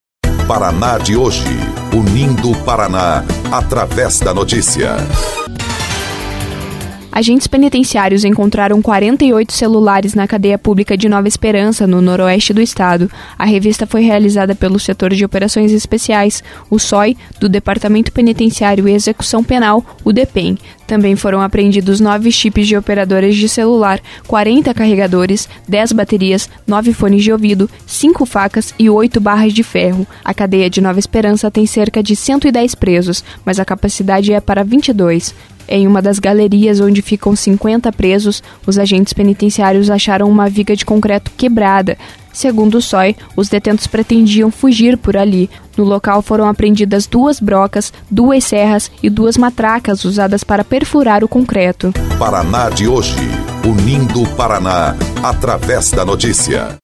06.06 – BOLETIM – Mais de 40 celulares foram apreendidos em Cadeia de Nova Esperança